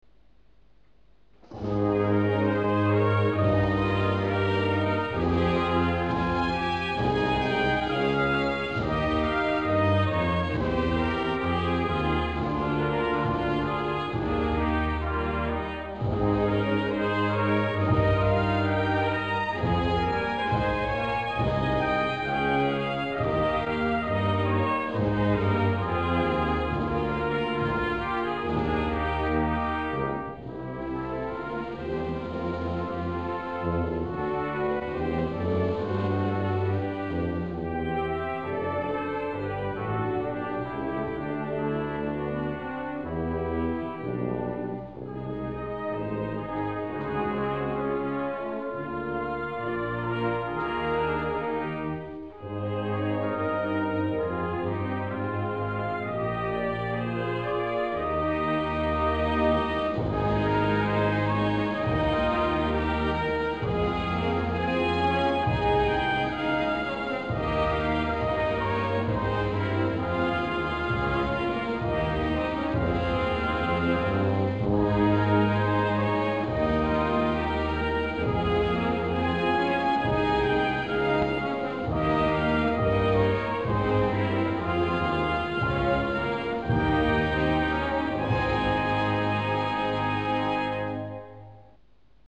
Альтернативная версия (новое звучание)